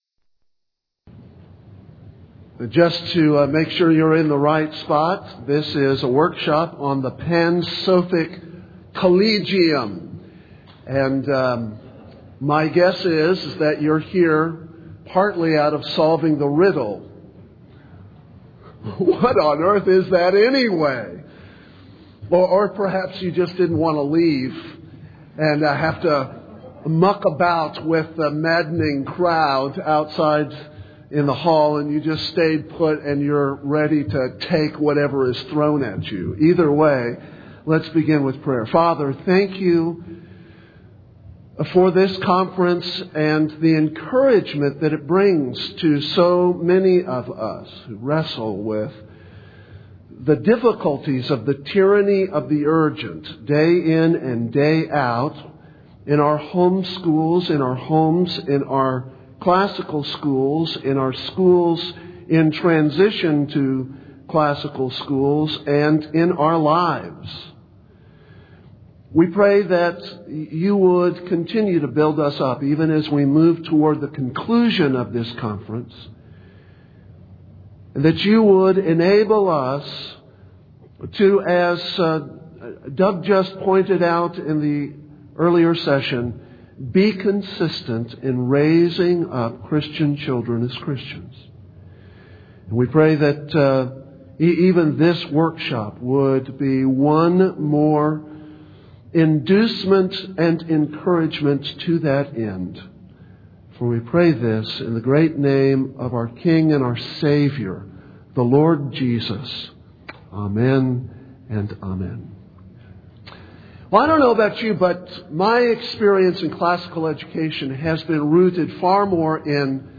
2004 Workshop Talk | 1:03:16 | All Grade Levels, Leadership & Strategic